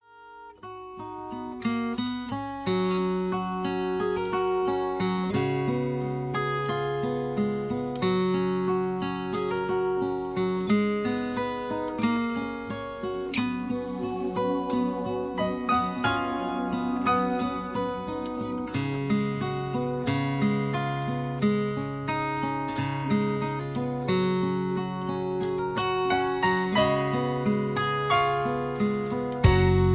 country